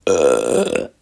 Burping/Belching
Category 🗣 Voices
air animation belch belching burp burping cartoon character sound effect free sound royalty free Voices